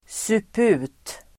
Ladda ner uttalet
Uttal: [²sup'u:t]
suput.mp3